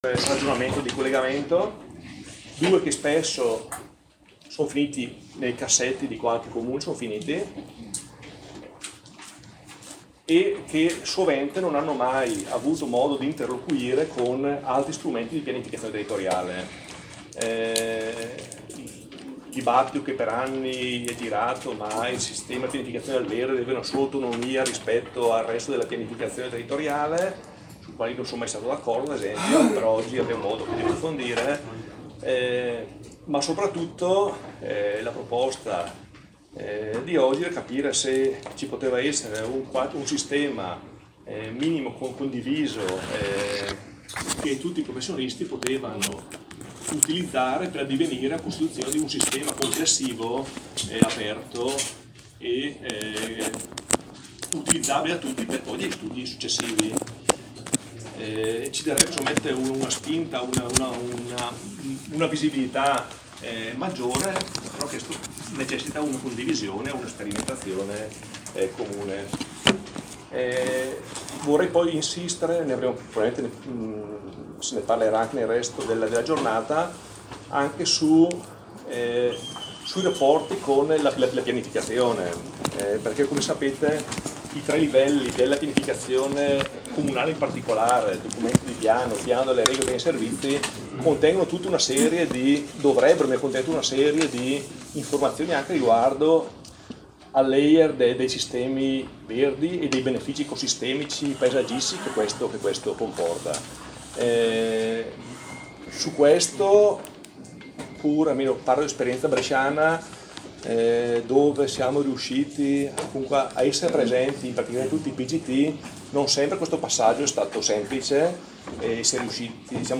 Brescia, 30 marzo 2017 – presso sede ODAF Brescia
Interventi al seminario